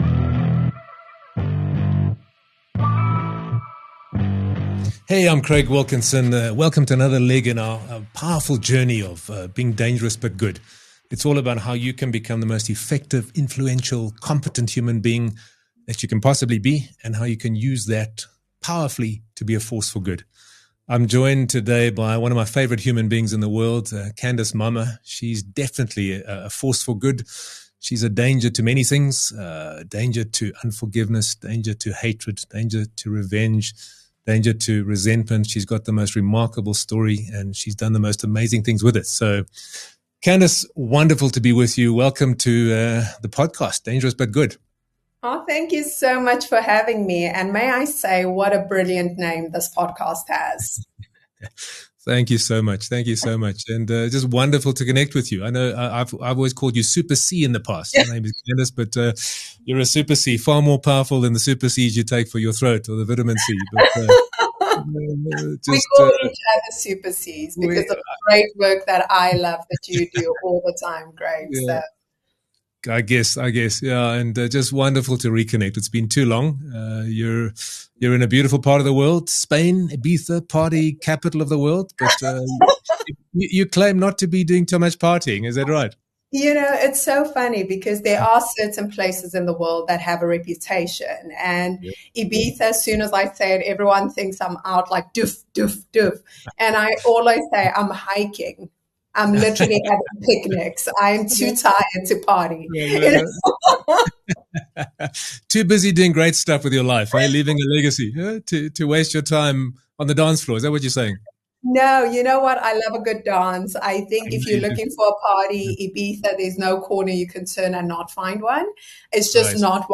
It liberates by breaking the hold the past has on us. This is a must listen conversation for anyone who has ever been wronged by anyone else, which is basically all of us.